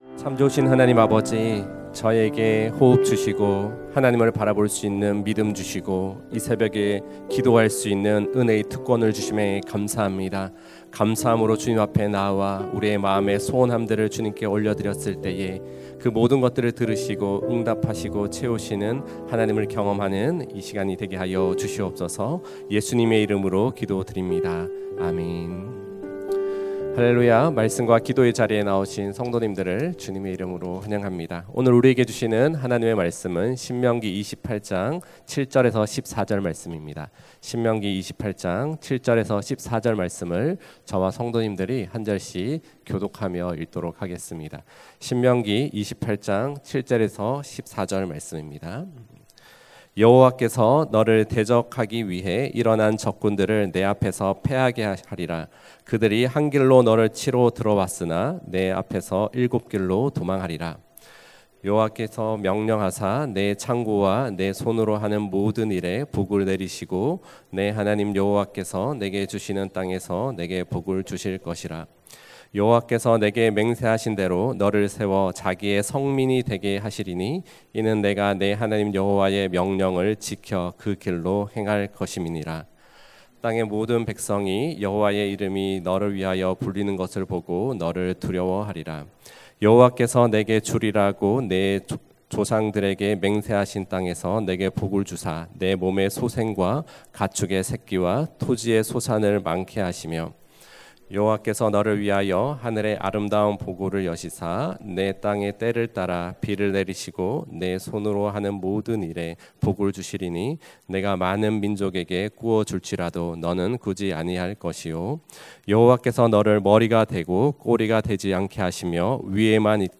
> 설교
[새벽예배]